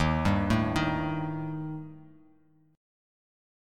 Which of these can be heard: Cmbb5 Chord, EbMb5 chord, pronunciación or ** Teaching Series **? EbMb5 chord